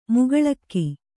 ♪ mugaḷakki